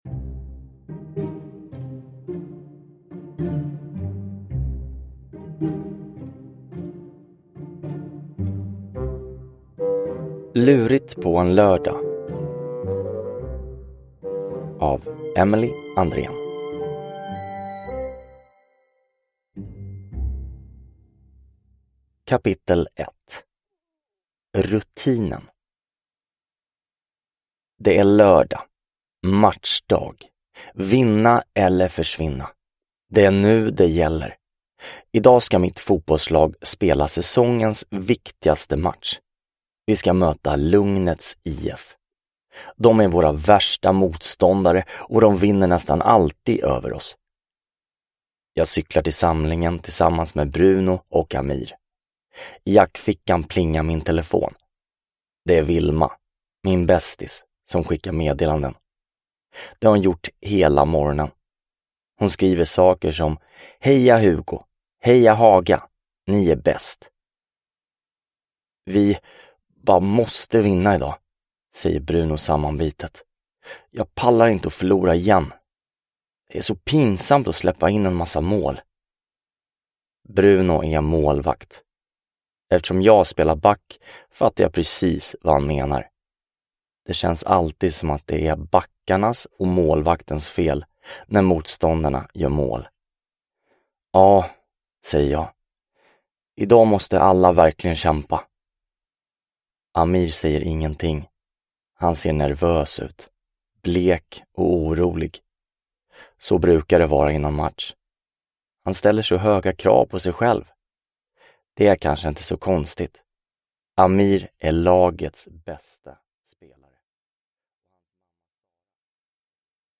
Lurigt på en lördag – Ljudbok – Laddas ner